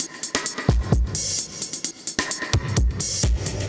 Index of /musicradar/dub-designer-samples/130bpm/Beats
DD_BeatD_130-02.wav